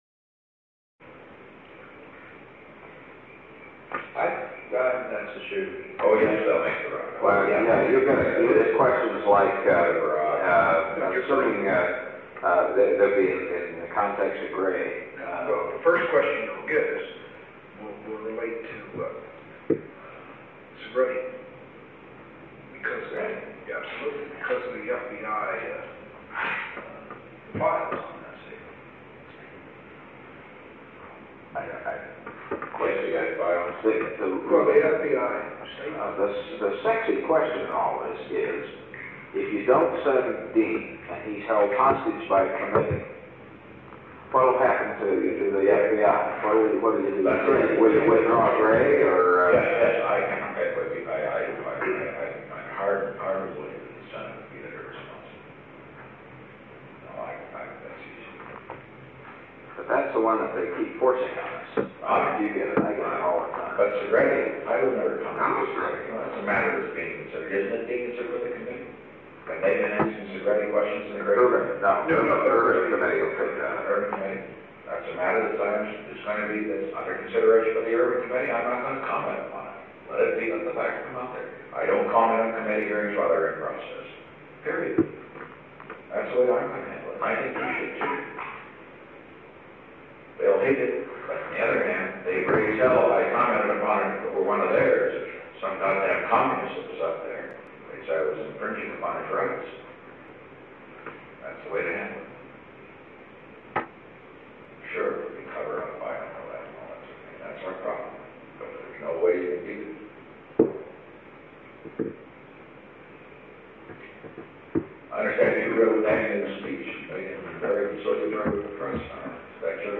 Secret White House Tapes | Richard M. Nixon Presidency